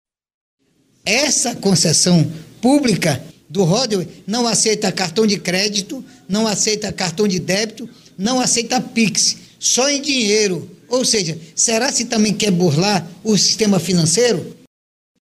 Sonora-2-Sinesio-Campos-–-deputado-estadual.mp3